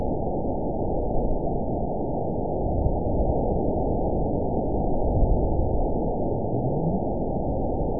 event 912565 date 03/29/22 time 12:59:16 GMT (3 years, 1 month ago) score 9.62 location TSS-AB04 detected by nrw target species NRW annotations +NRW Spectrogram: Frequency (kHz) vs. Time (s) audio not available .wav